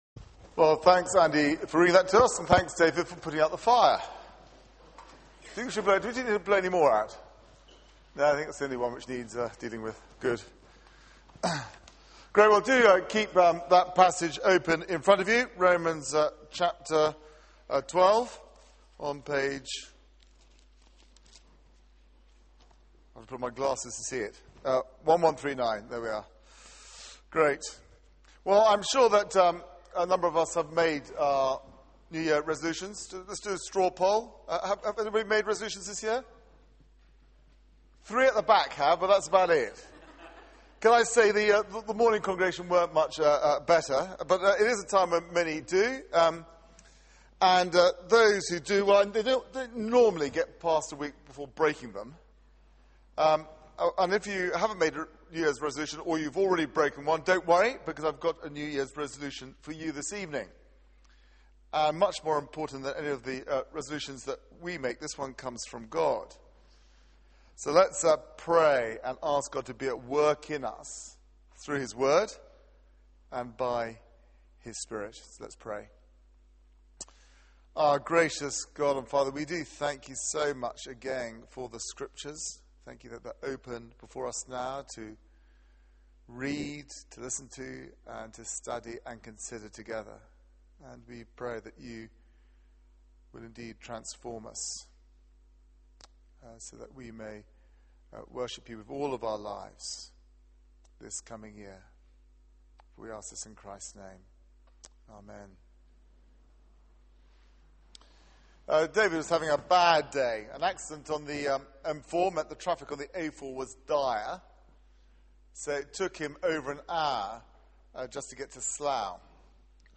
Media for 6:30pm Service on Sun 01st Jan 2012 18:30 Speaker
New Years Day Theme: New Year Sermon Search the media library There are recordings here going back several years.